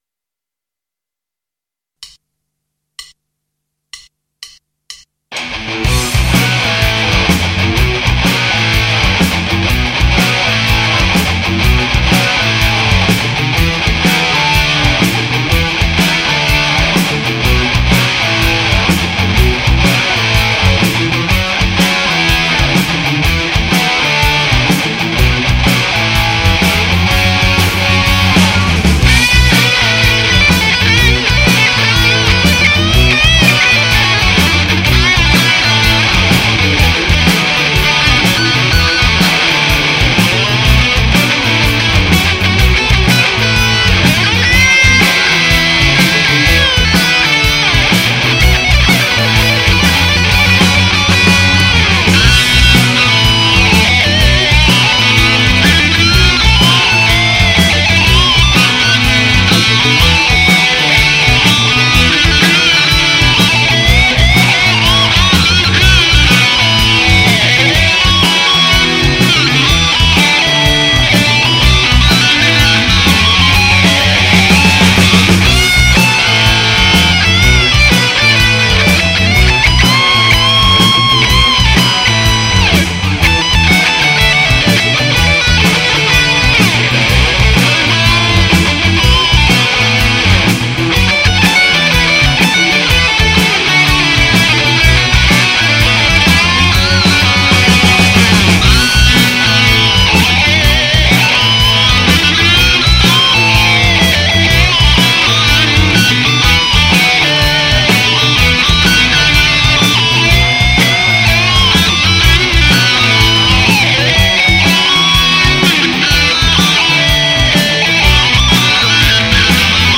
Pre Recorded Backing Track